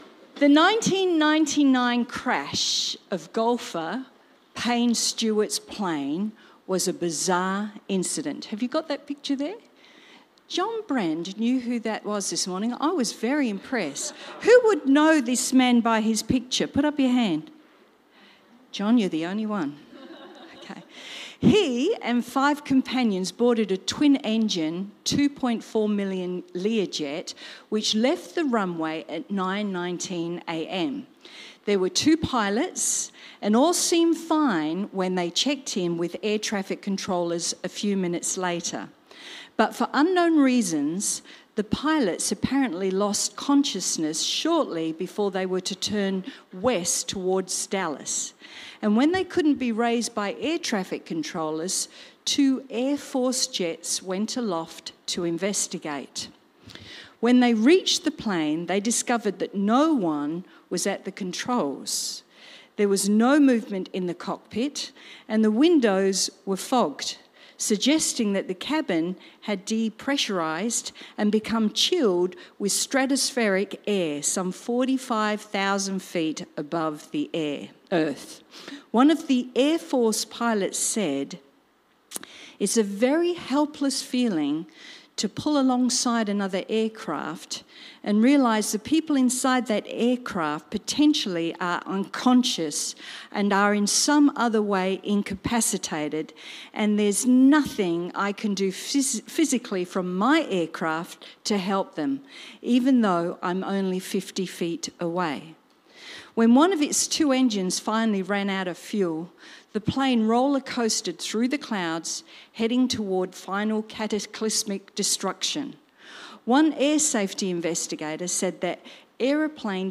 Cityview-Church-Sunday-Service-Judgement-Day.mp3